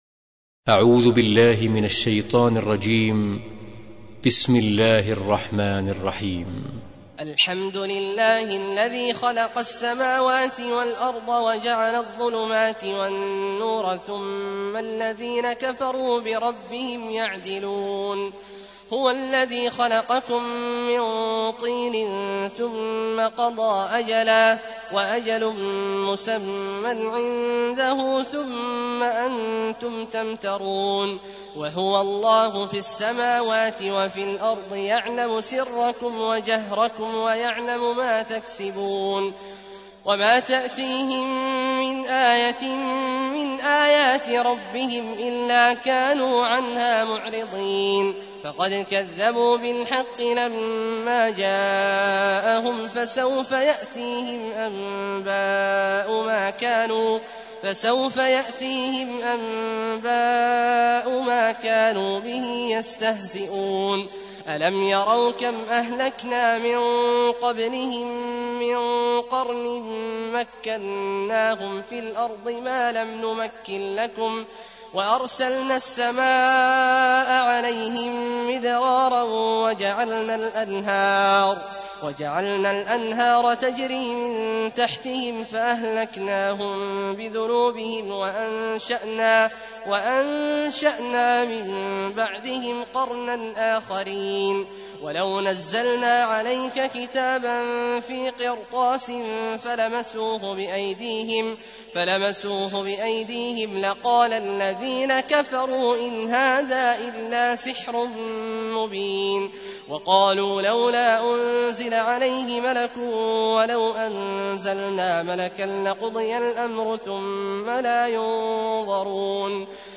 تحميل : 6. سورة الأنعام / القارئ عبد الله عواد الجهني / القرآن الكريم / موقع يا حسين